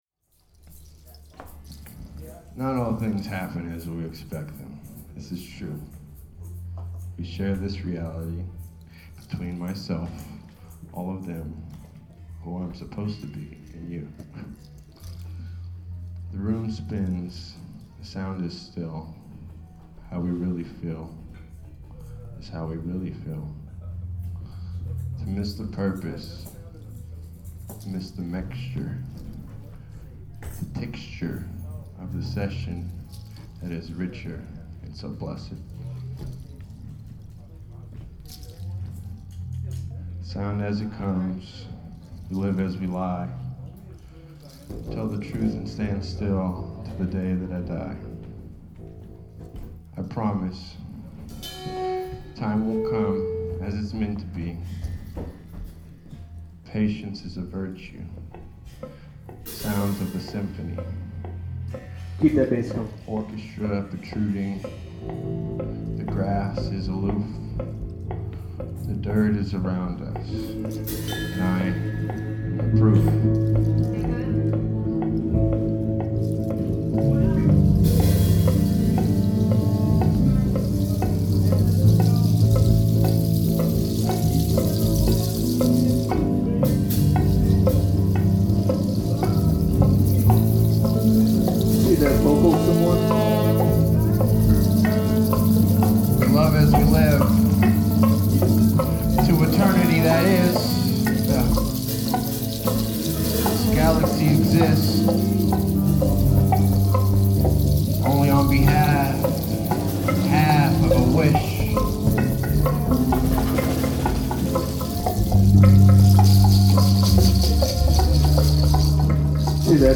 The 'Floating-Roster Ever-Expanding Live-Album Blog'
guitar
sax
bass
voice
drums
percussion